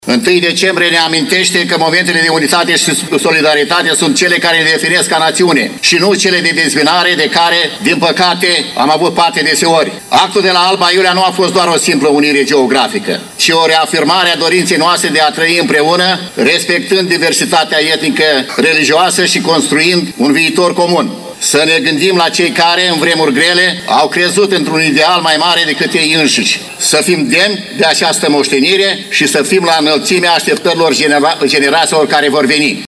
Ziua Națională a fost marcată astăzi la Suceava printr-un ceremonial militar la Monumentul Bucovina Înaripată.
La rândul său, primarul municipiului Suceava VASILE RÎMBU a scos în evidență faptul că Ziua Națională “poartă în ea toate idealurile acestui popor”.